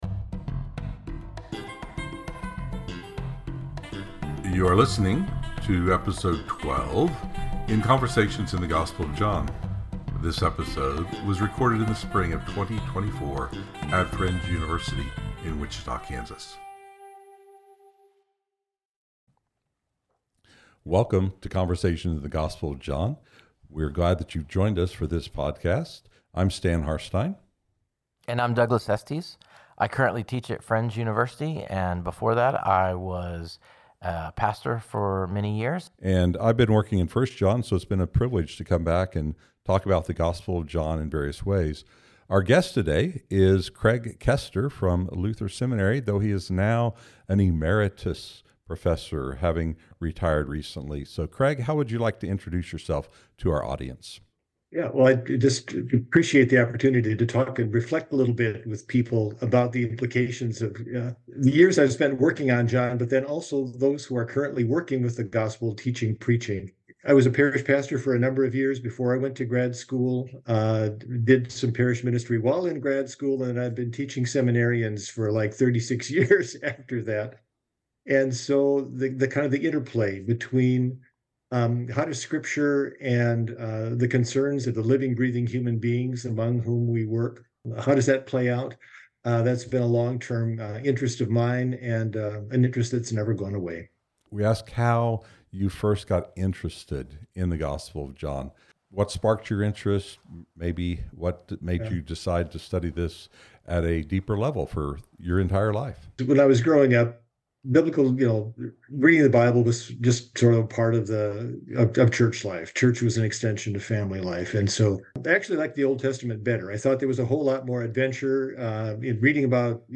Johannine scholars to discuss how their research can impact preaching and teaching.